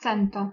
Ääntäminen
Synonyymit centurie cent Ääntäminen France: IPA: [sɑ̃.tɛn] Haettu sana löytyi näillä lähdekielillä: ranska Käännös Ääninäyte Substantiivit 1. cento Suku: f .